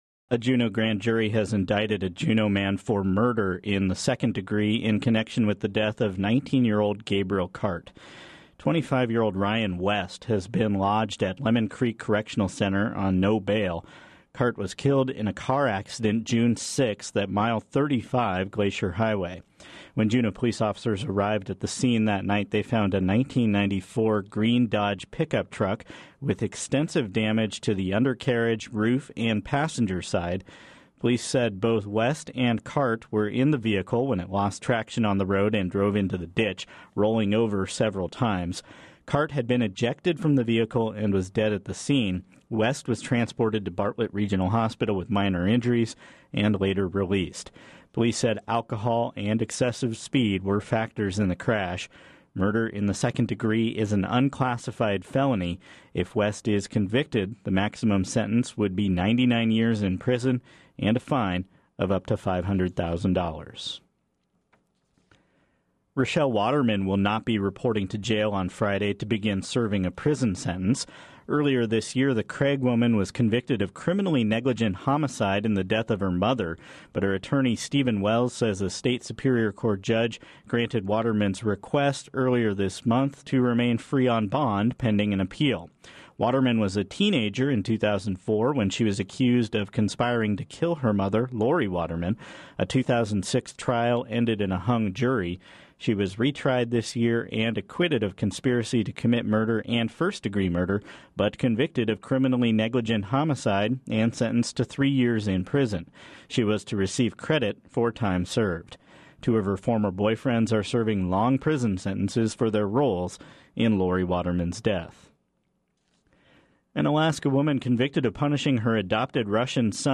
Friday Newscast